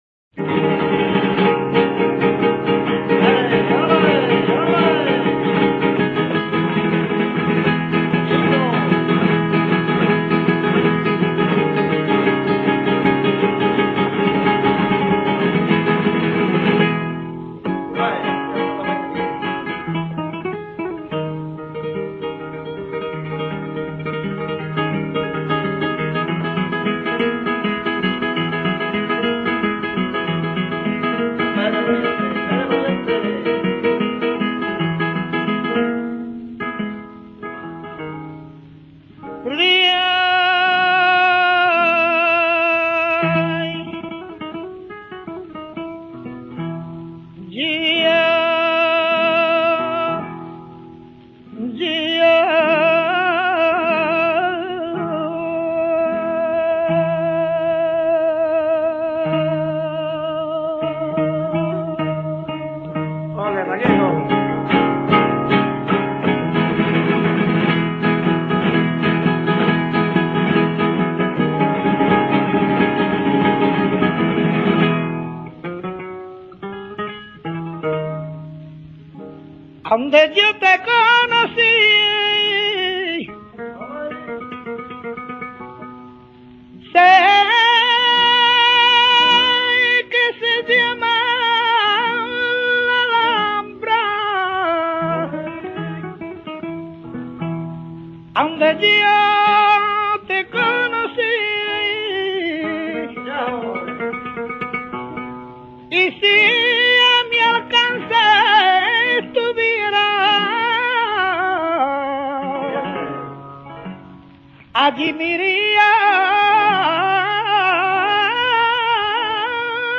MEDIA GRANA�NA.f. Cante con igual copla que la grana�na y que pertenece al grupo de cantes de Levante. M�s afiligranado que las grana�nas y m�s brillante, su creaci�n se le atribuye a don Antonio Chac�n. Jos� Blas Vega, ha expuesto sobre su origen y desarrollo la siguiente teor�a: �La grana�na era un cante que a Chac�n le ven�a corto.
mediagranaina.mp3